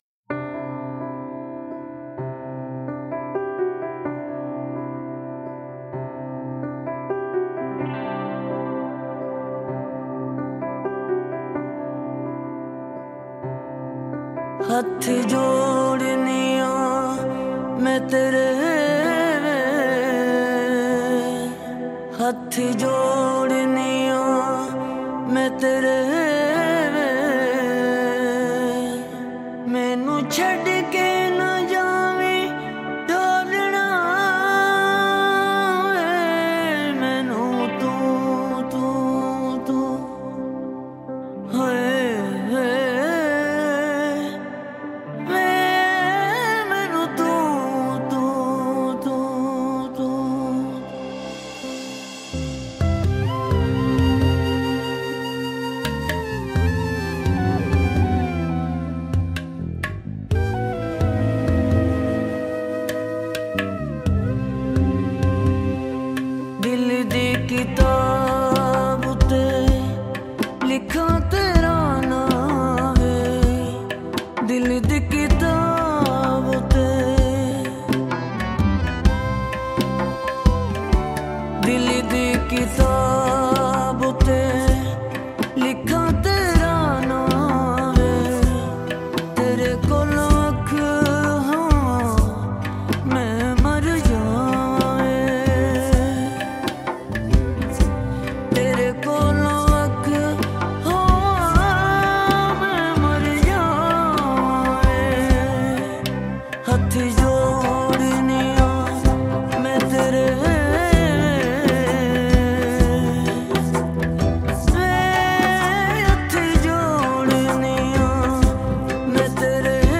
Kalaam/Poetry , Punjabi